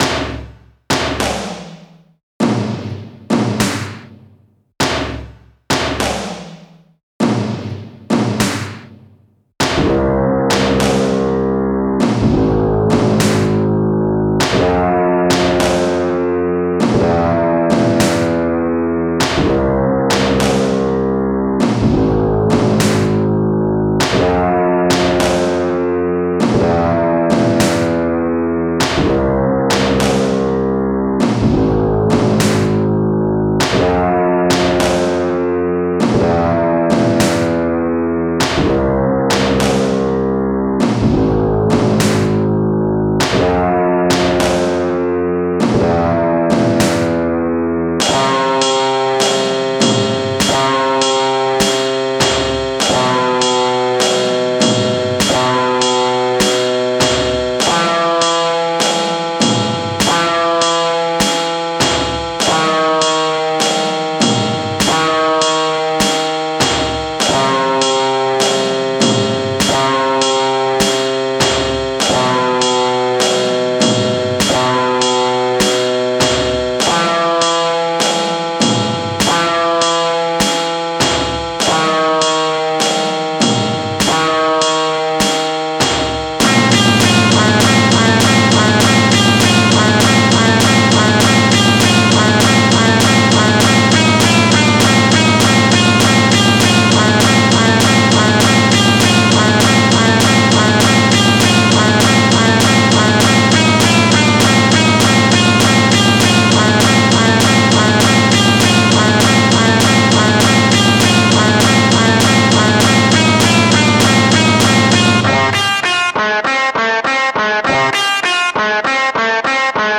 weird, electronica, surreal, experimental,